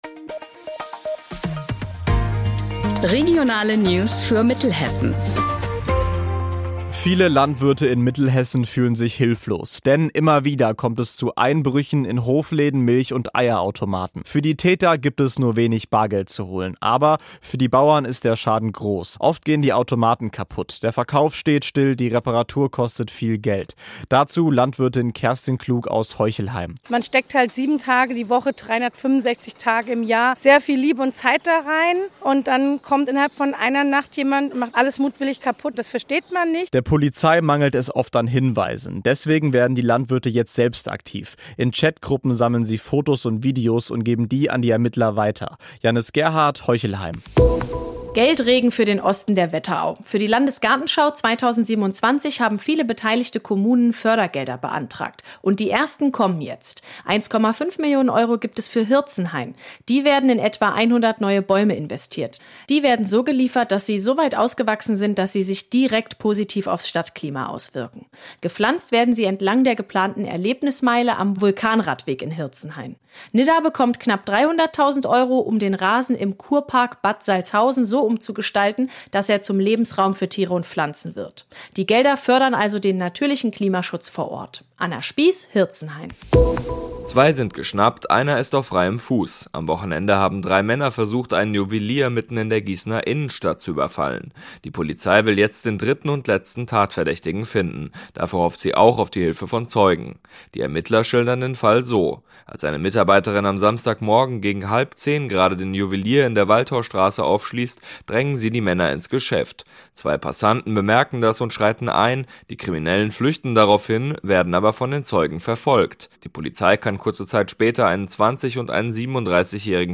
Mittags eine aktuelle Reportage des Studios Gießen für die Region.